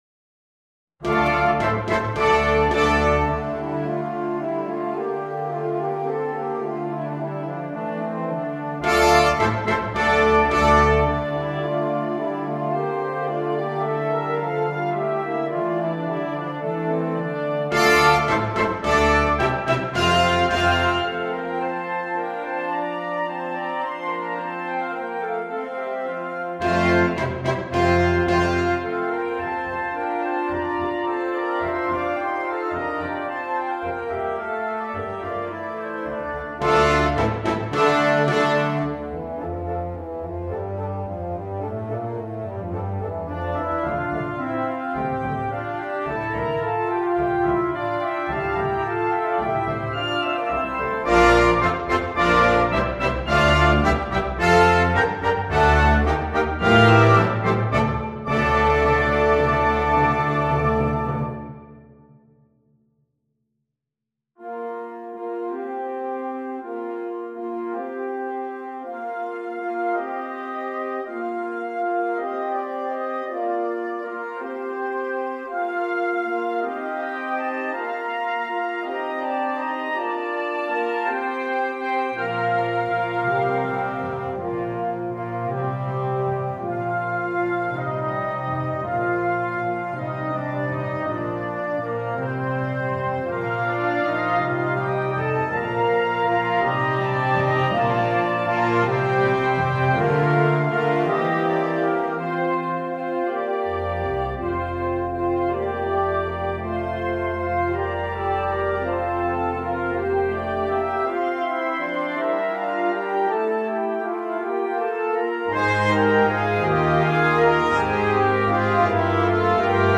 Genre: 6-Part Flex Band
# of Players: 6+
Part 1: Flute/Oboe, B-flat Clarinet
Percussion 1: Tambourine, Woodblock
Percussion 2: Tubular Bells (Chimes)
Percussion 3: Snare Drum, Suspended Cymbal